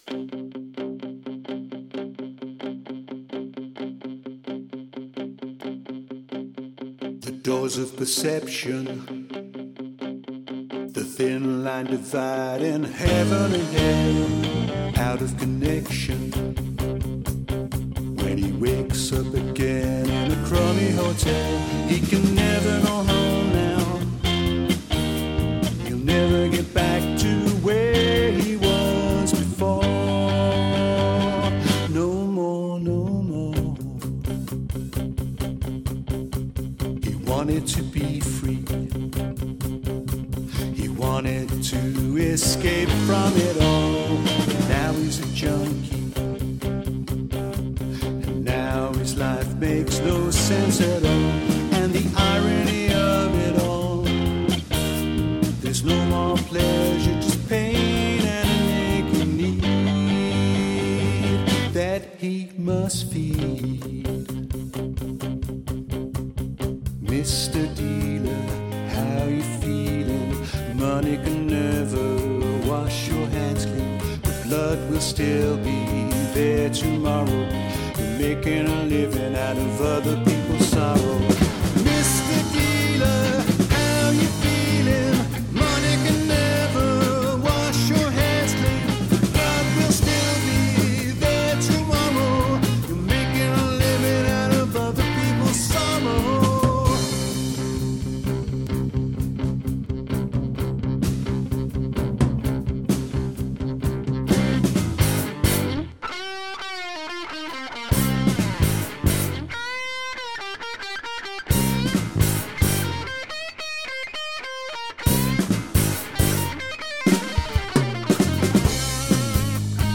Nous jouons essentiellement du rock, funk et reggae.